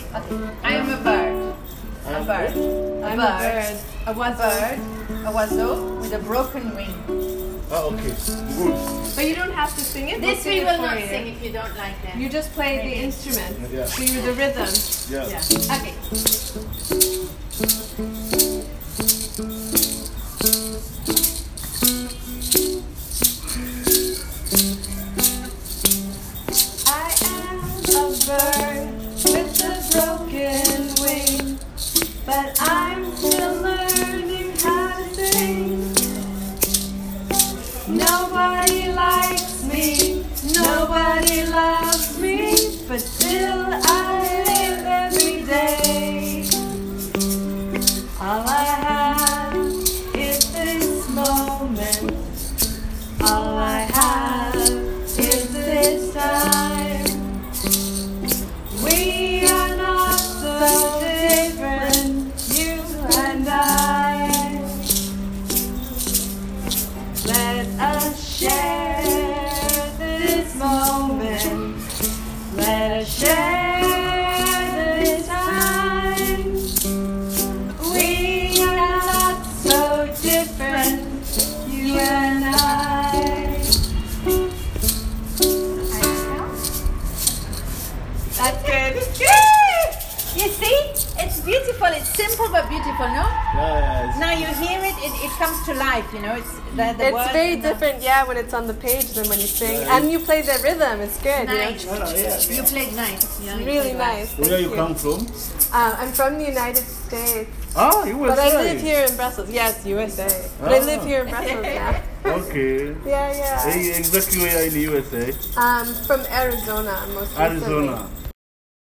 This is a recording of the first version of the song from the Monday afternoon in September: